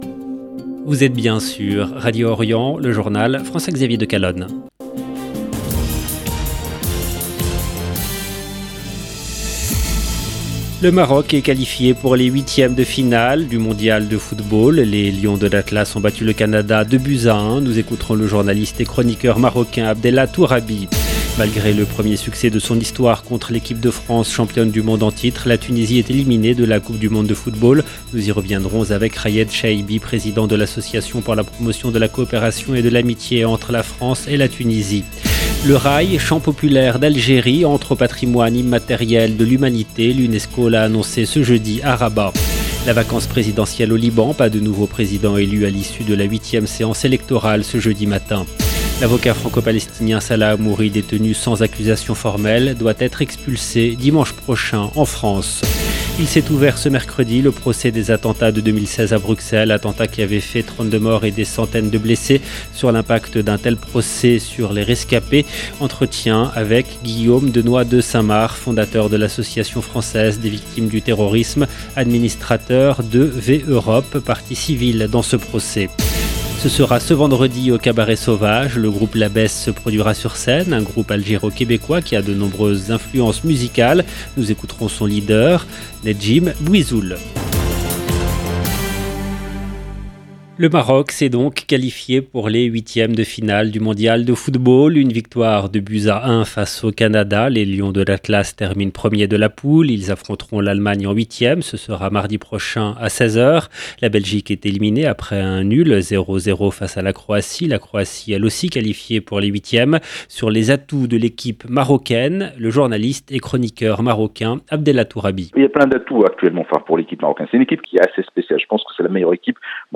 LE JOURNAL DE 22 H EN LANGUE FRANCAISE DU 1/12/2022